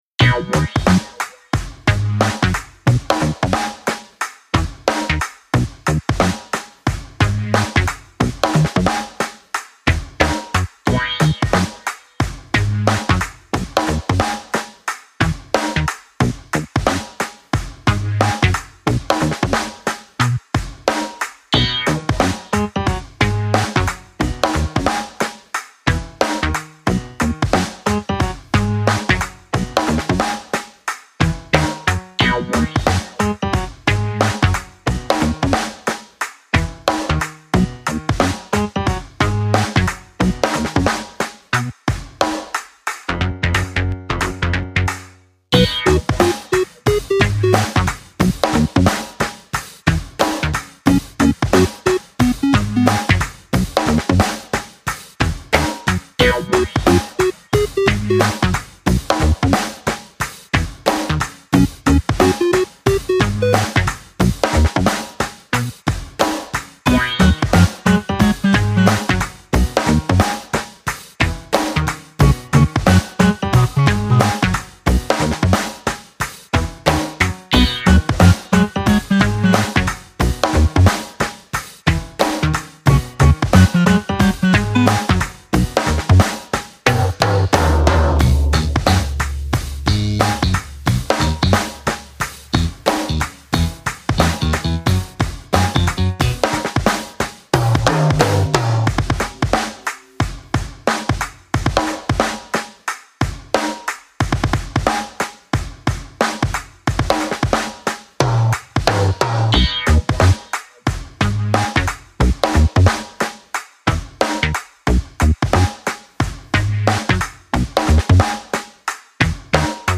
【用途/イメージ】　おもしろ動画　ダイジェスト映像　可笑しい　楽しい　笑い